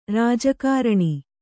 ♪ rājakāraṇi